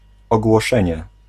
Ääntäminen
Synonyymit advertentie verkondiging bericht annonce Ääntäminen : IPA: /ˈaːn.kɔn.də.ɣɪŋ/ : IPA: [ˈaːn.kɔn.də.ʝɪŋ] Haettu sana löytyi näillä lähdekielillä: hollanti Käännös Ääninäyte 1. ogłoszenie {n} Suku: f .